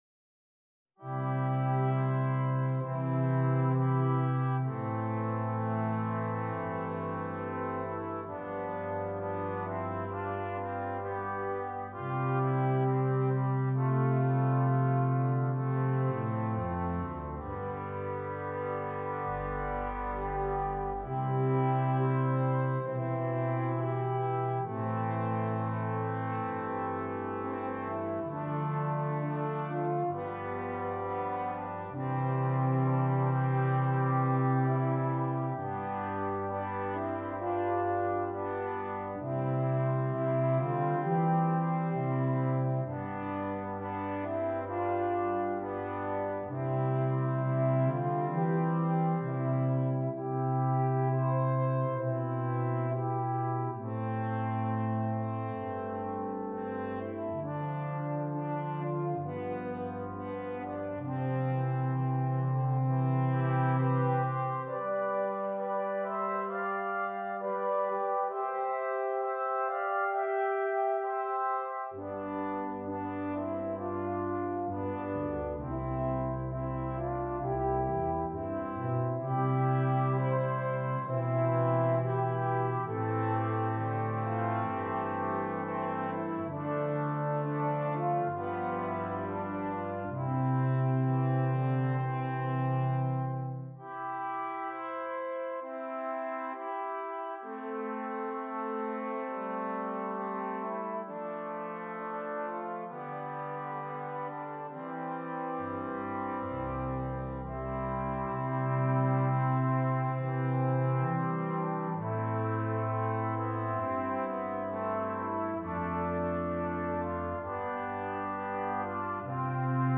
Brass Quintet
Traditional Austrian Carol